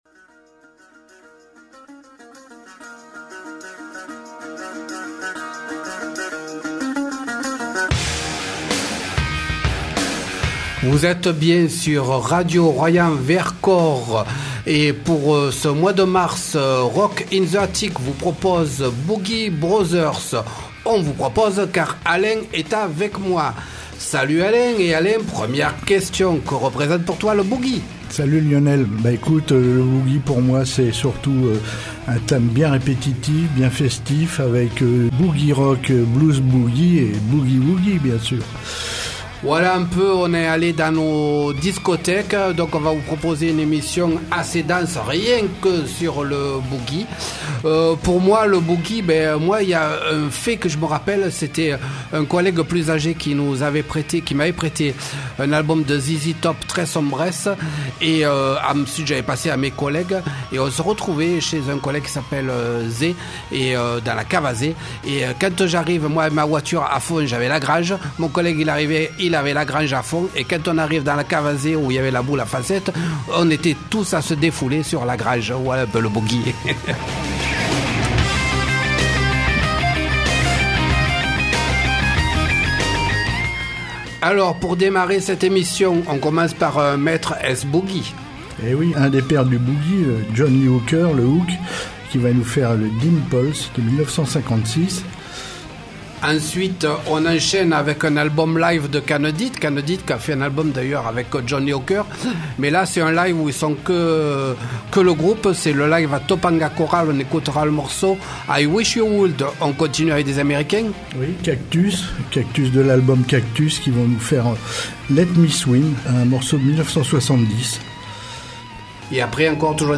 Nous sommes allés chercher dans nos discothèques ce genre musical qui invite à la danse, la fête et le défoulement.